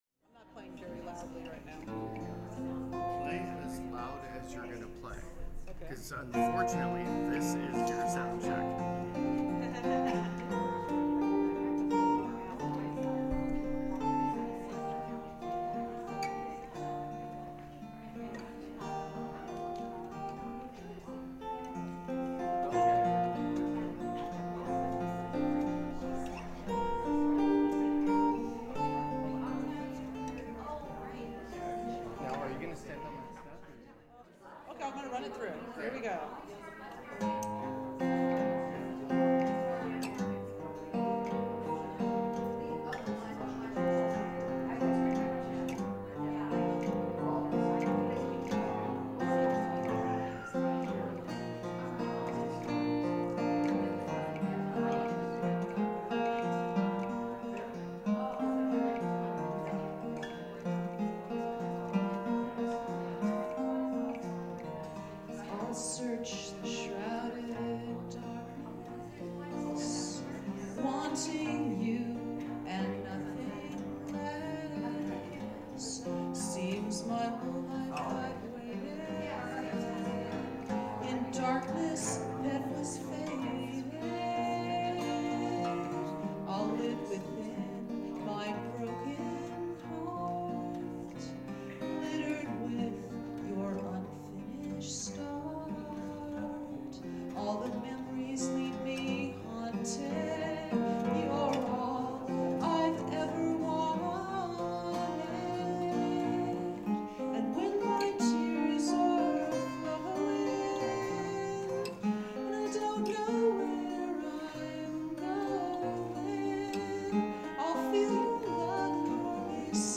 9/16/10 SOUND CHECK PRACTICE RENDITION OF “BESIDE ME ALWAYS”
I drove to our temple for a sound check that was in preparation for my performance there on Saturday.
The soundman inserted a wire into my new pickup.
I hit another wrong chord, but hoped it wasn’t noticed.
It was noisy as I began my song. The temple choir was on a break and everyone was chatting away. After a few lines of my singing, the room became quieter.